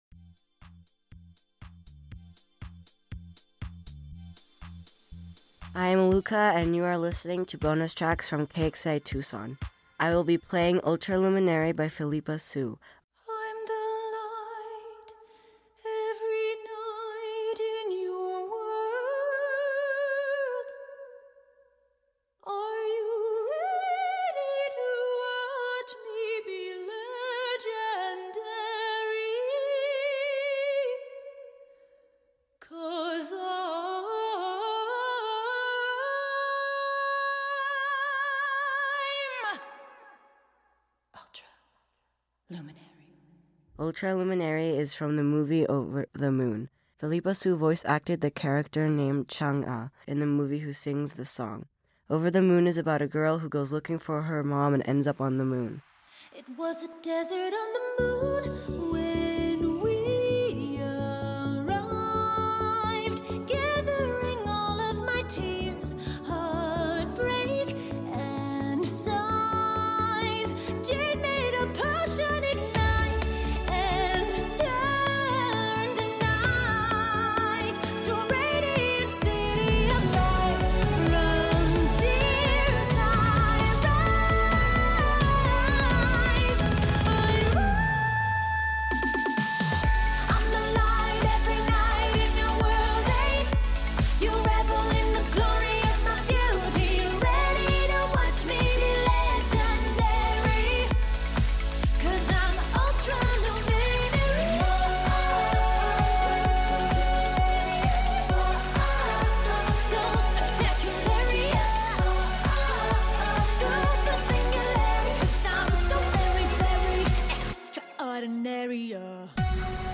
Song Review